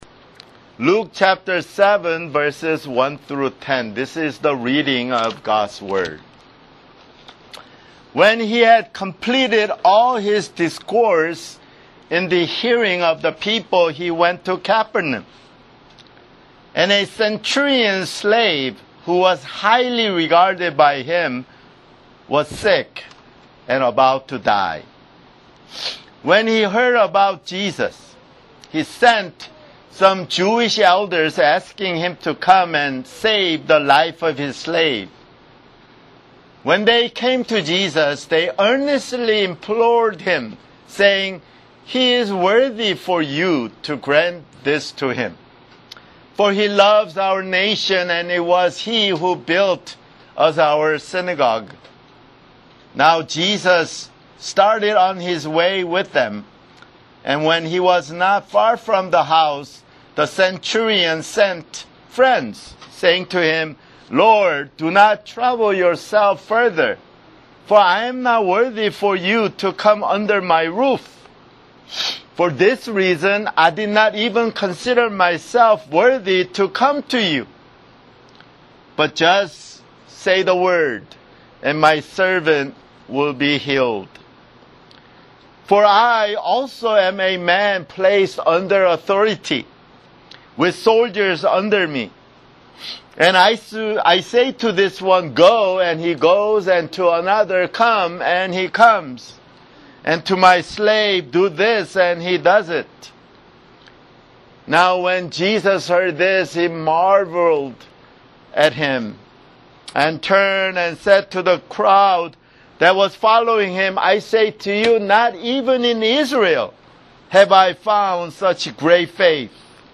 [Sermon] Luke (51)